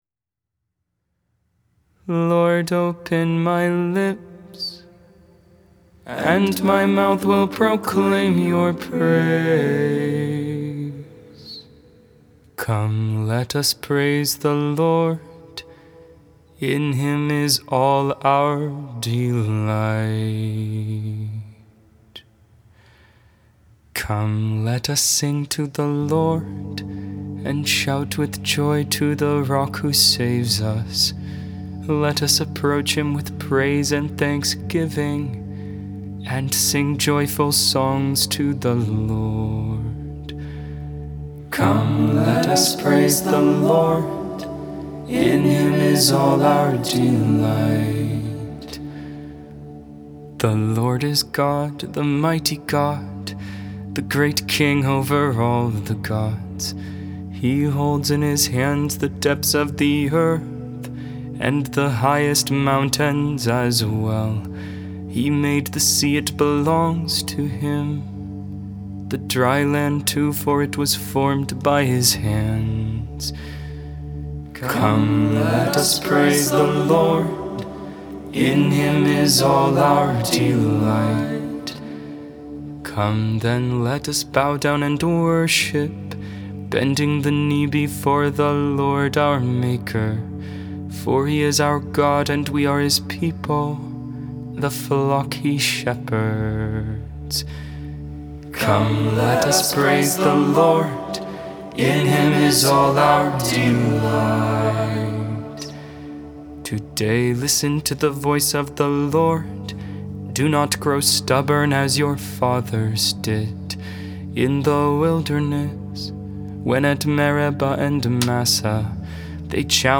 Psalm 51 (Gregorian tone 1)